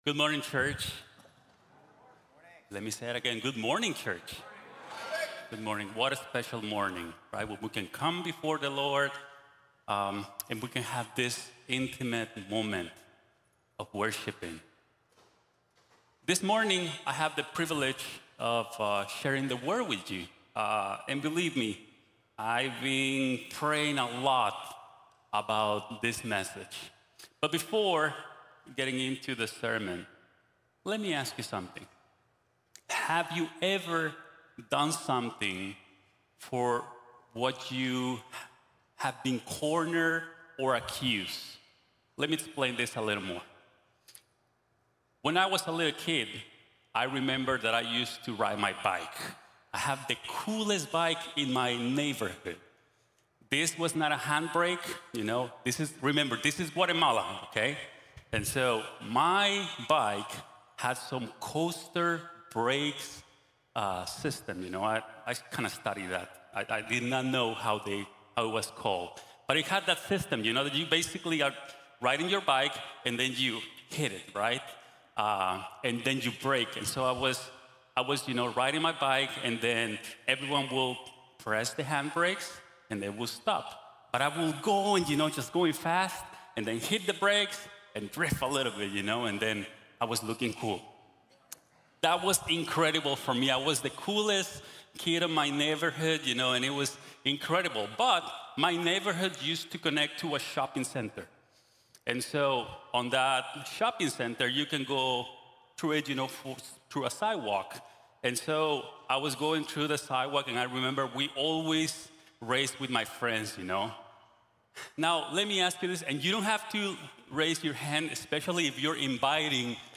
Hamartiología | Sermón | Iglesia Bíblica de la Gracia